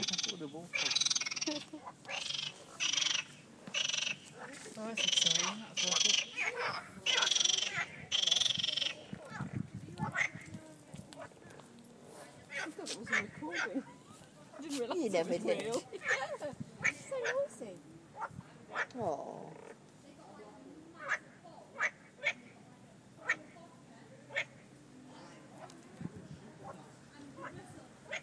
Noisy bastard frogs